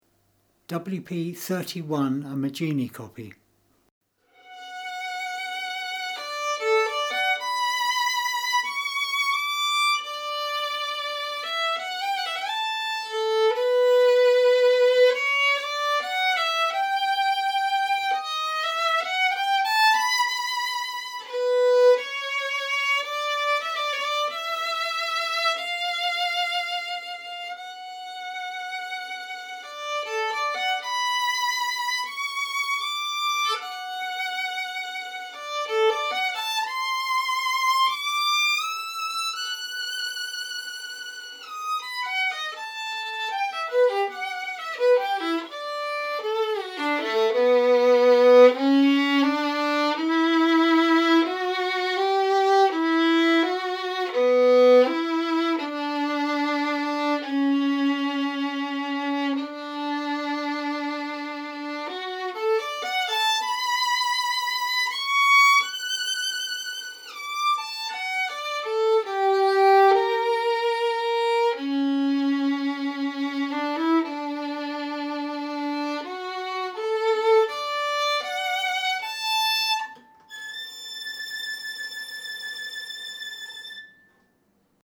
A nice quality Maggini copy violin made around 1920.